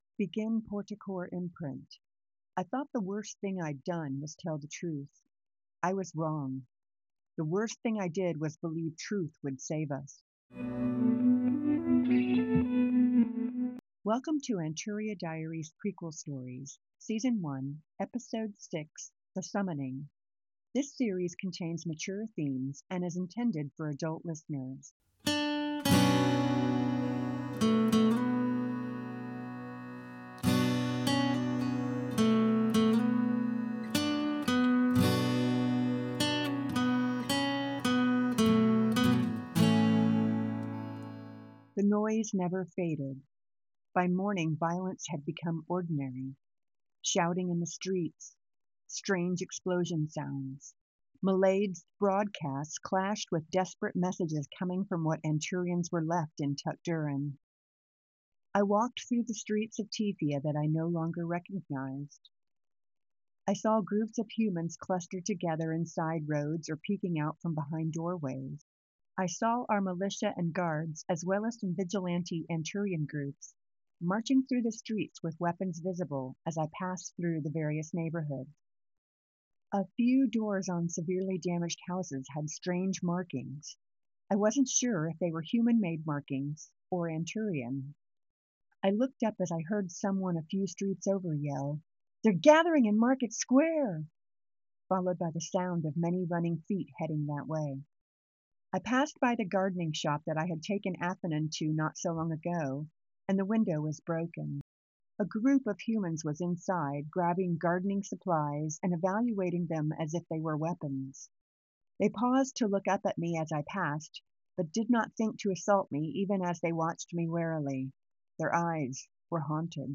This is a stylized companion to the Anturia Diaries: Prequel Stories audio drama—crafted for readers who prefer to experience the story on the page.